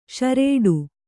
♪ ṣarēḍu